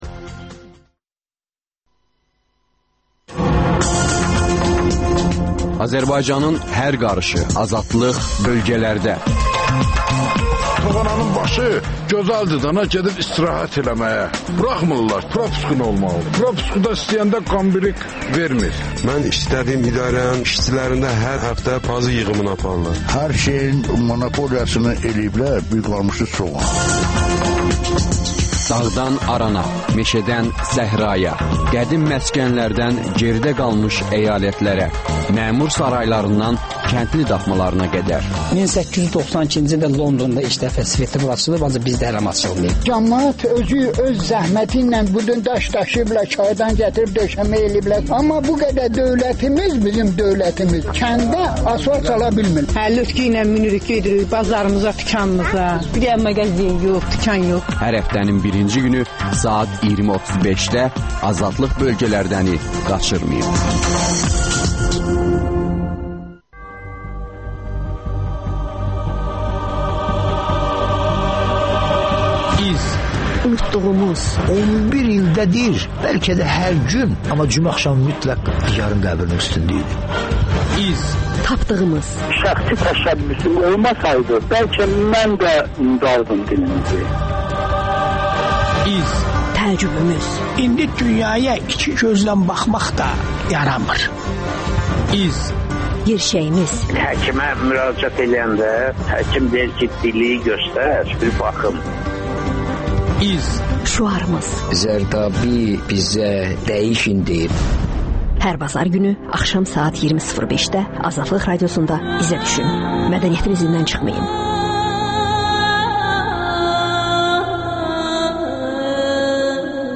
debatı.